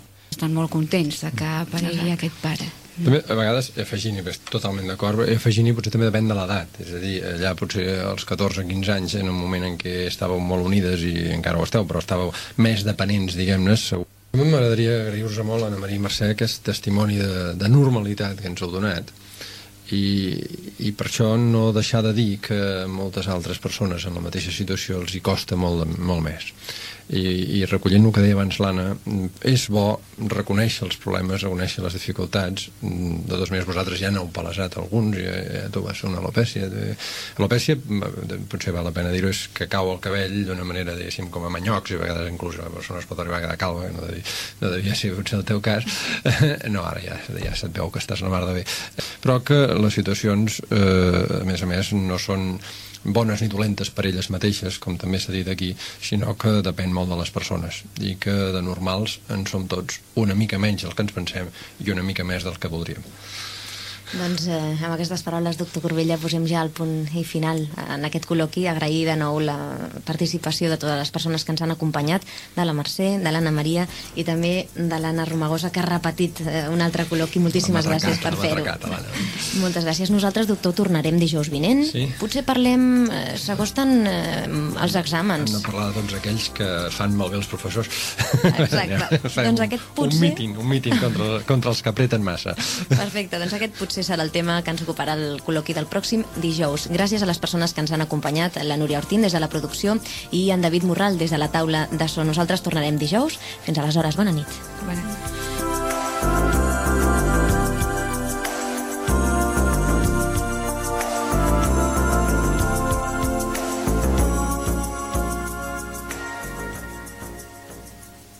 Final del col·loqui, comiat del programa i equip
Divulgació
FM